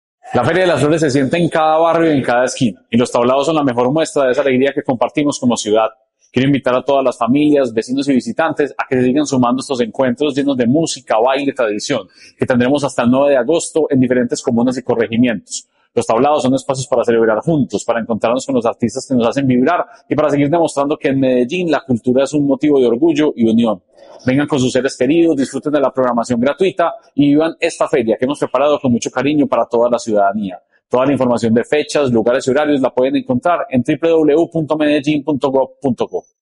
Declaraciones del secretario de Cultura Ciudadana, Santiago Silva Jaramillo
Declaraciones-del-secretario-de-Cultura-Ciudadana-Santiago-Silva-Jaramillo.mp3